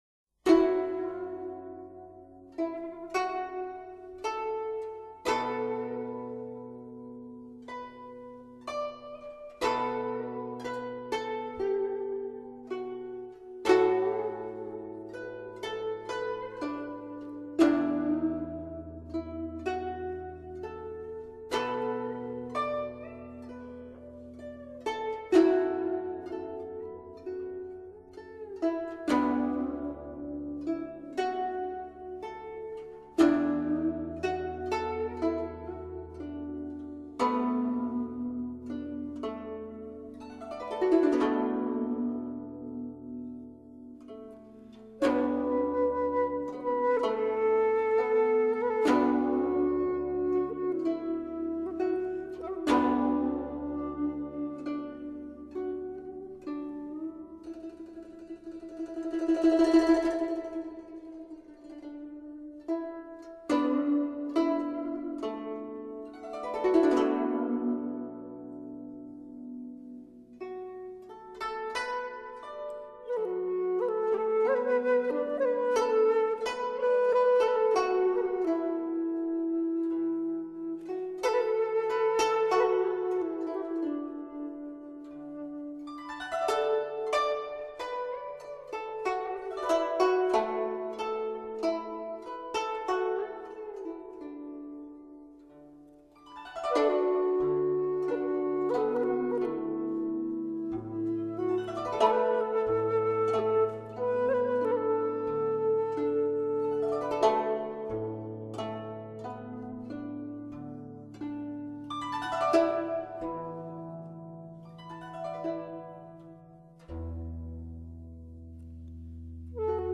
萧伴奏